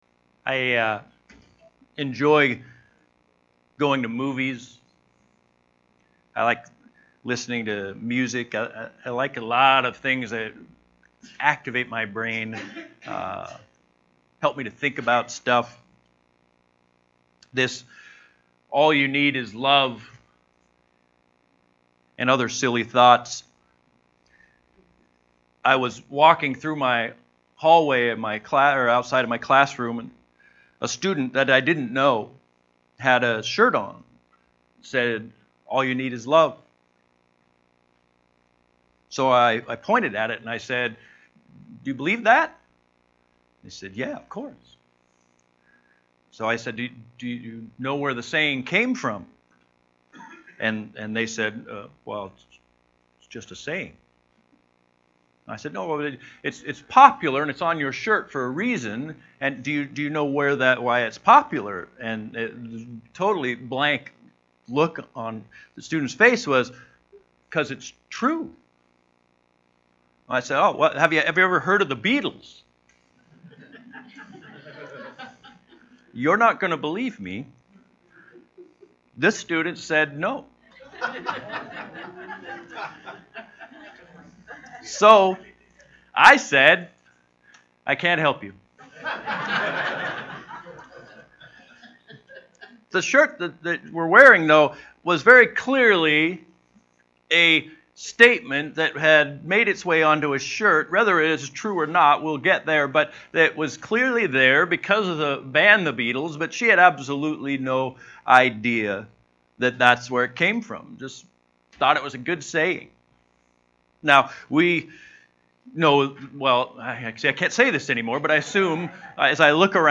Download Download From this series Current Sermon Love is All You Need What is Love?